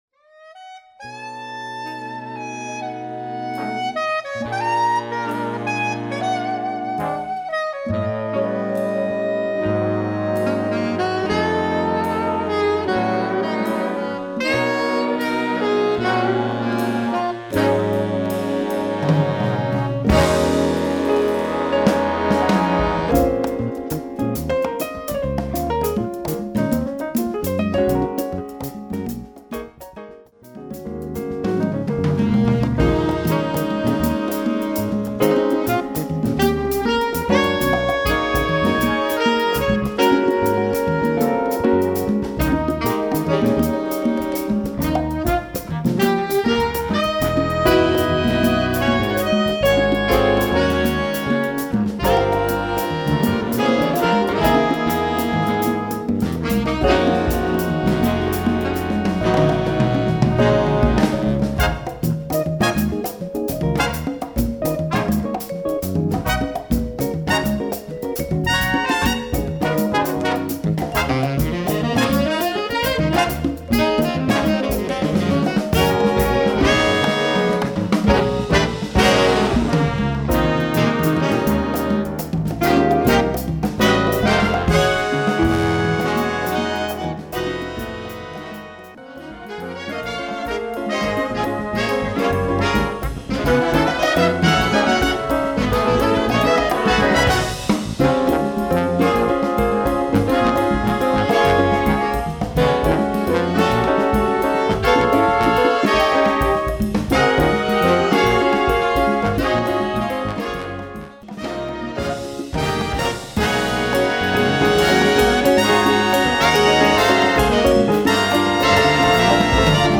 Category: Latin Jazz Fusion (4-4-5, 6 rhythm)
Style: “Funky” Latin Jazz
Solos: alto 1, tenor 1, trumpet 4 trombone 1
Instrumentation: big band (4-4-5, guitar, vibes, rhythm (6)